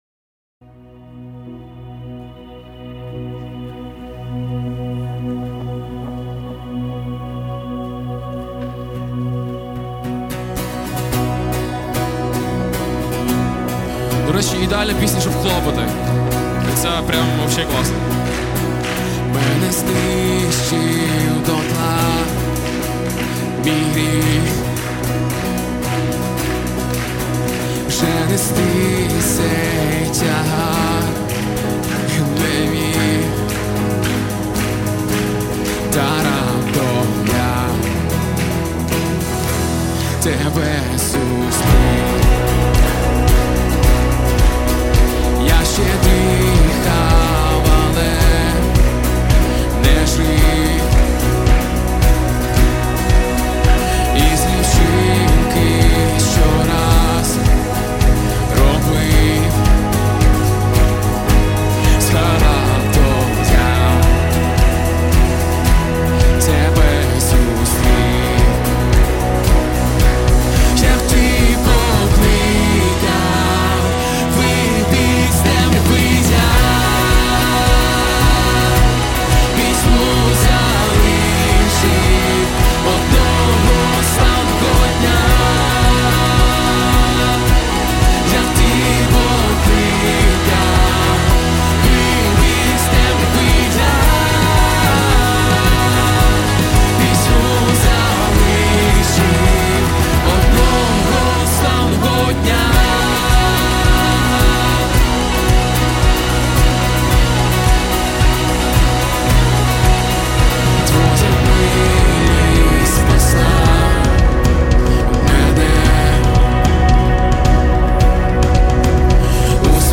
Worship Music
200 просмотров 210 прослушиваний 5 скачиваний BPM: 110